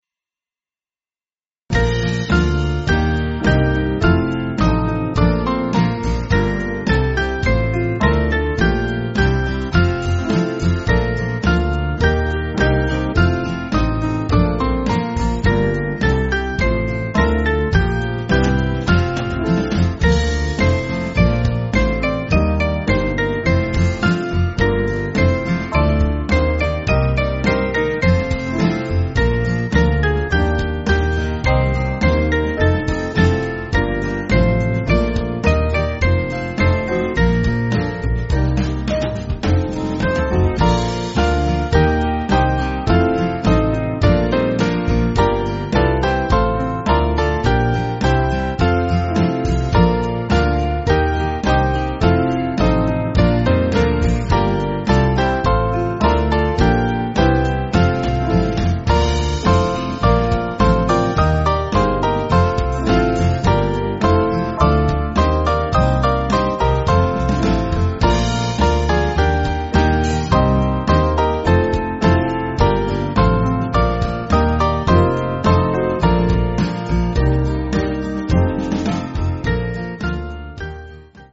Small Band
(CM)   4/Bb